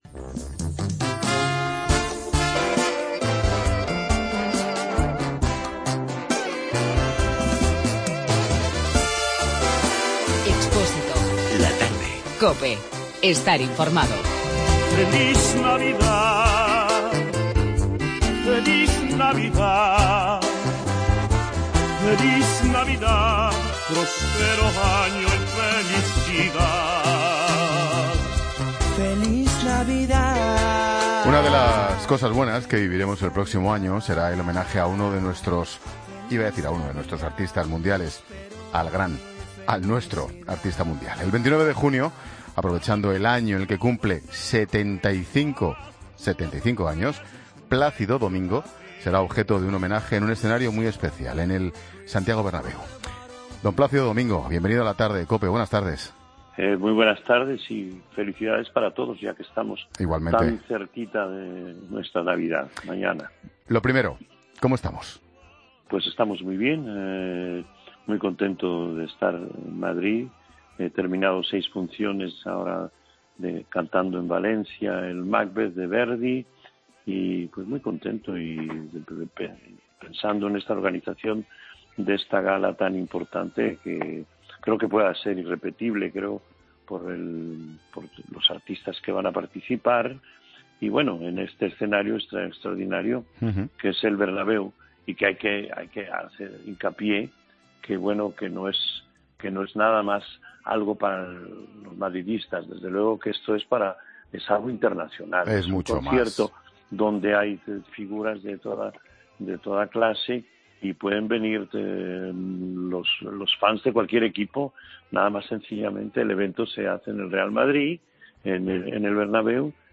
AUDIO: El prestigioso tenor español nos habla del concierto "Plácido en el alma" que se celebrará en su honor en el estadio Santiago...